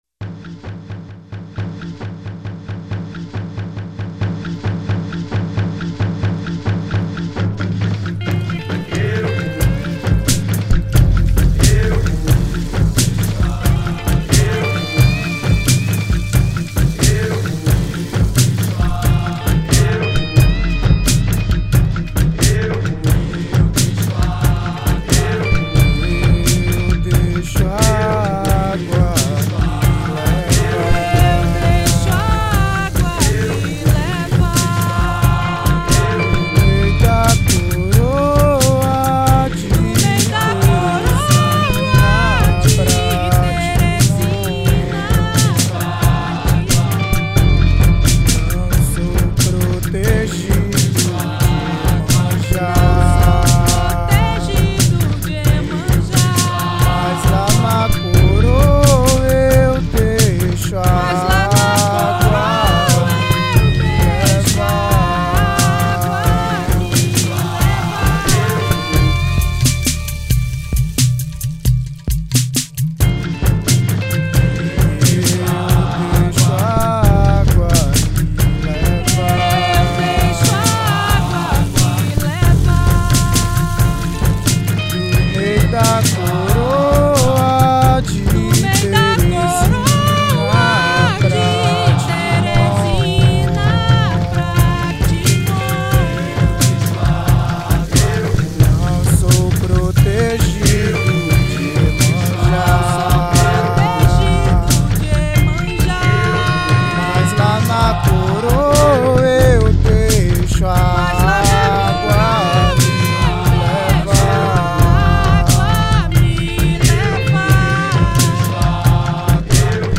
1946   03:06:00   Faixa:     Rock Nacional